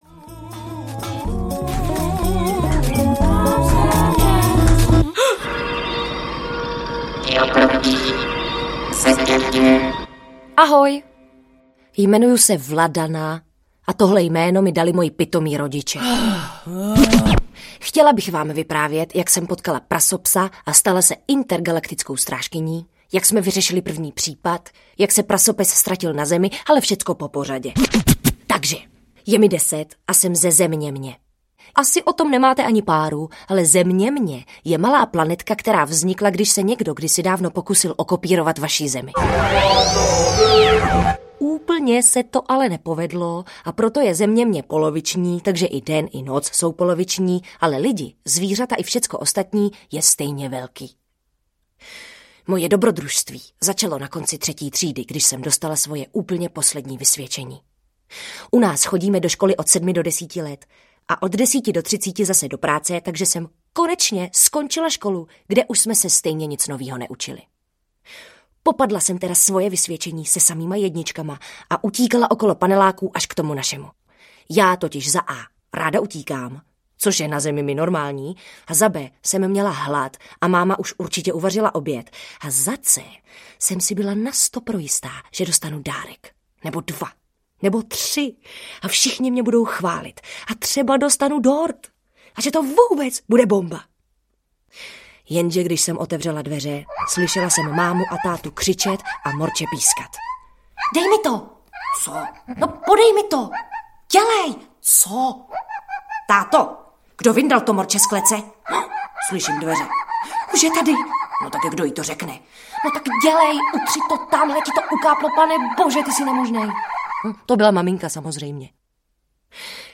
Vladana a Prasopes (komplet) audiokniha
Ukázka z knihy